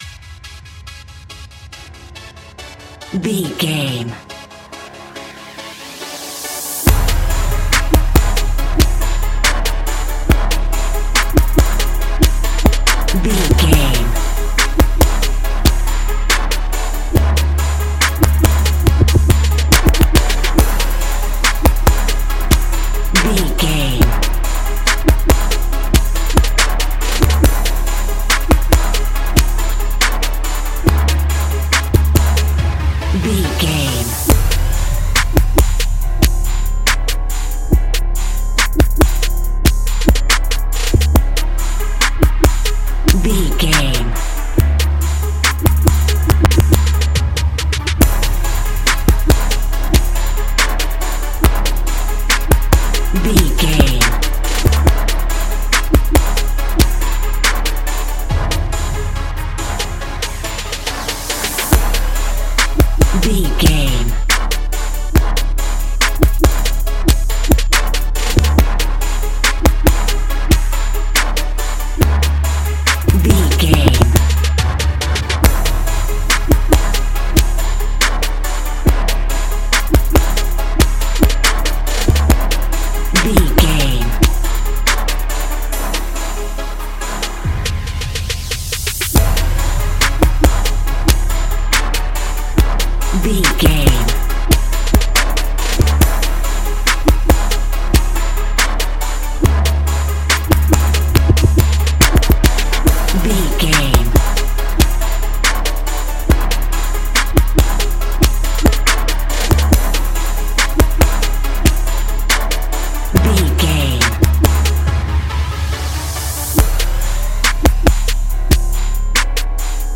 Aeolian/Minor
C#
Funk
hip hop
synths
synth lead
synth bass
synth drums